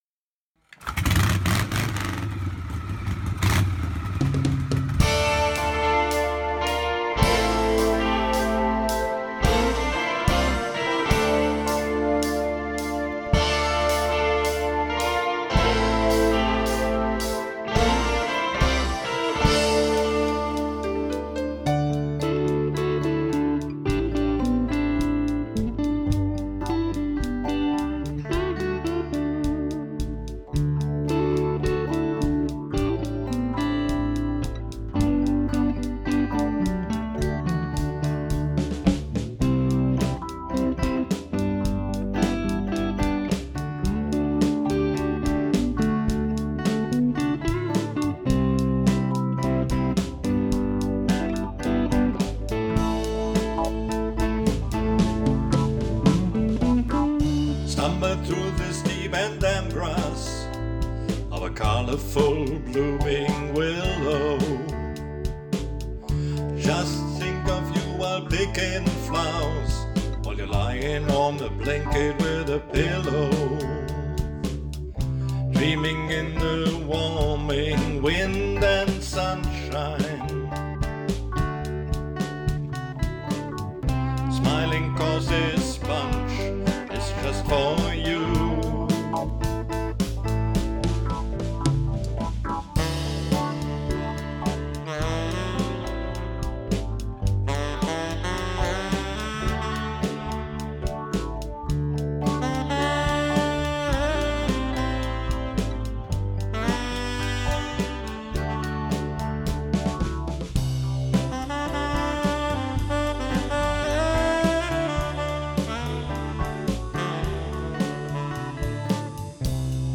Lead-Gitarre
Drums
Bass
Keyboard
Acoustic
Voices
Saxophon
Gitarre